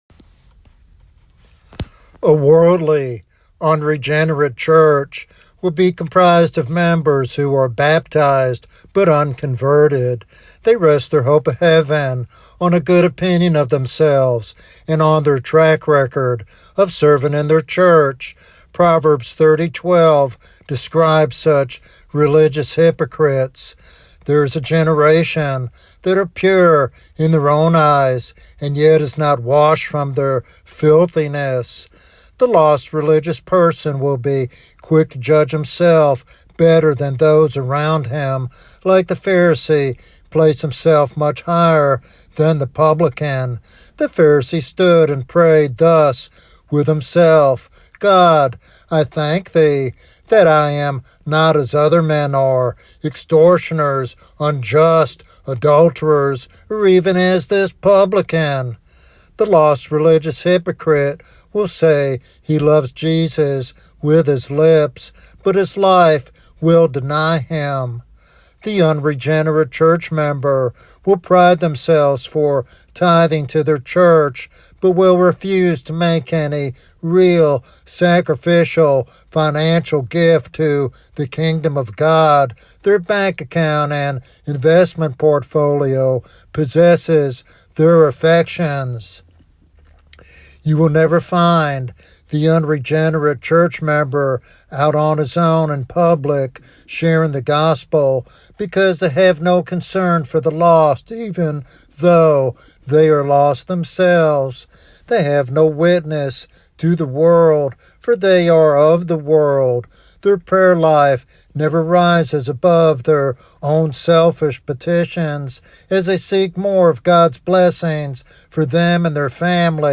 In this topical sermon